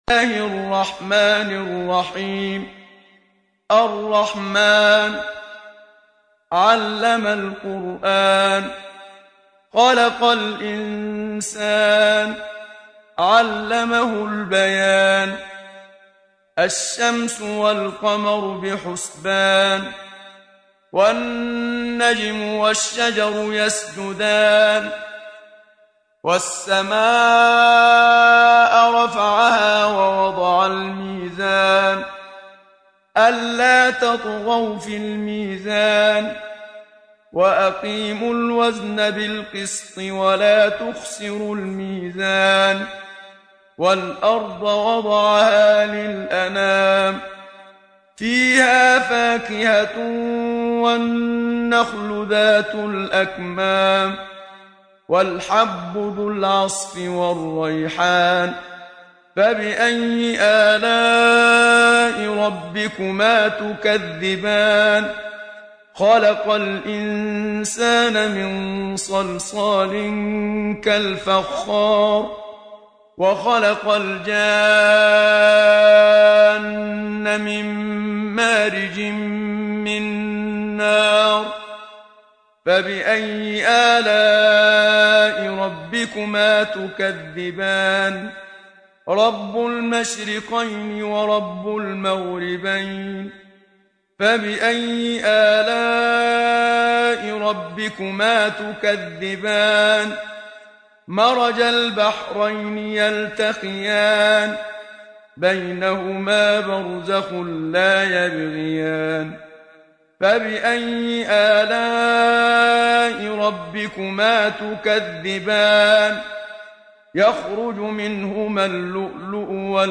سورة الرحمن | القارئ محمد صديق المنشاوي